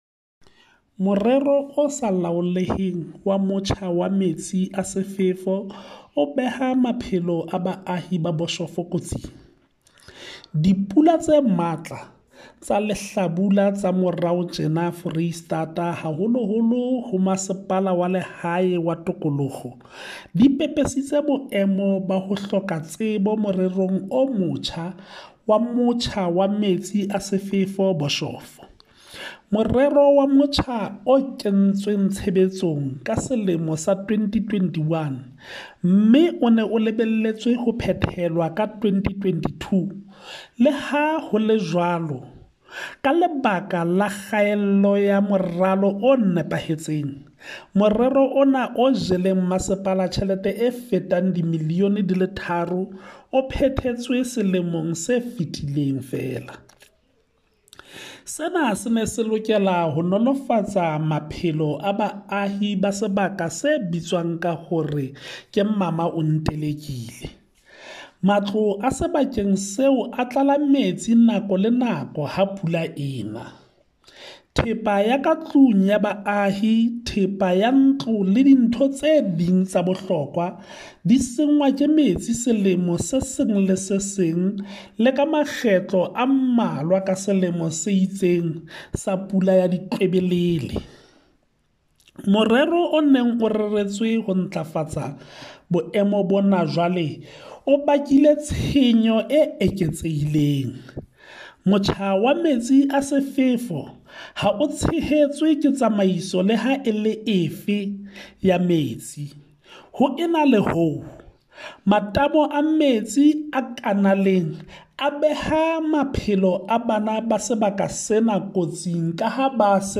Sesotho soundbites by Cllr Hismajesty Maqhubu and